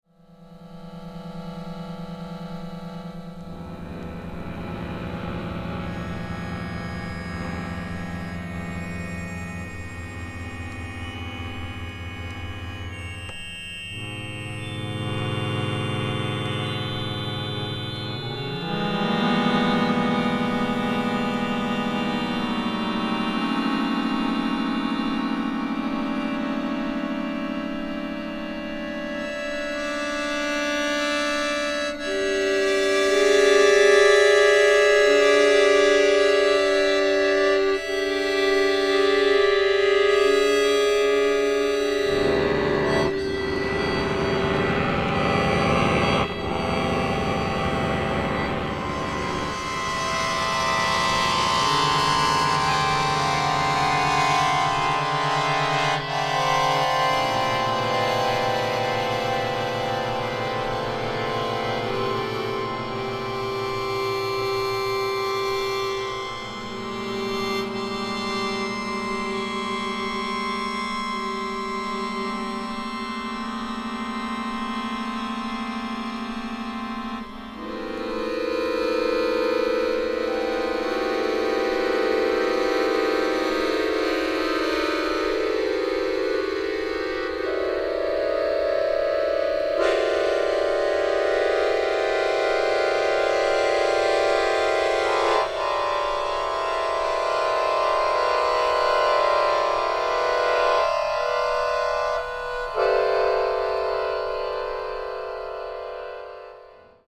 キーワード：ドローン　即興　フォーク